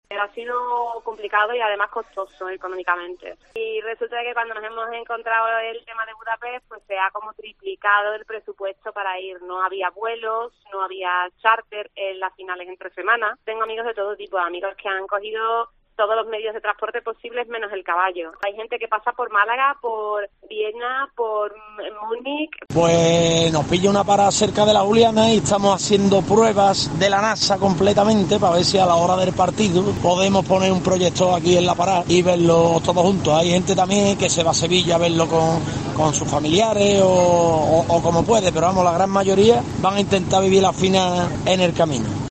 Escucha a la afición del Sevilla FC que se prepara para ver la final de la Europa League